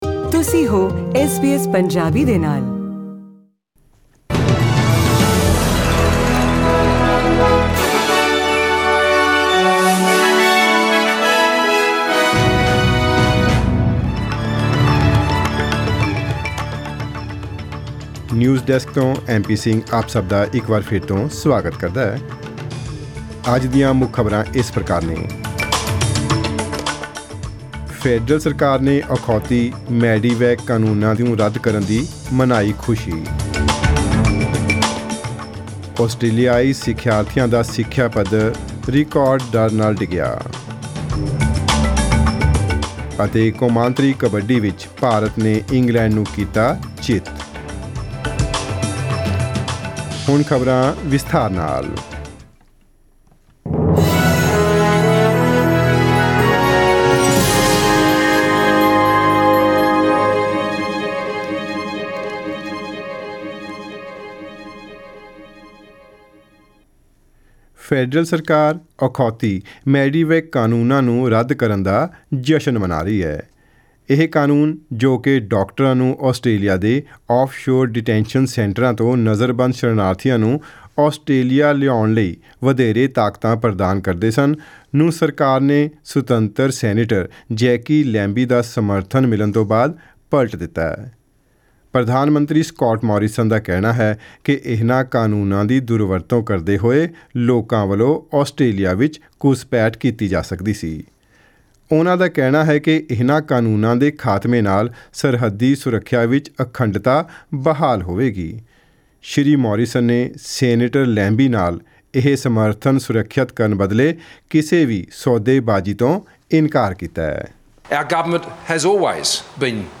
Here are the headlines in tonight’s news bulletin: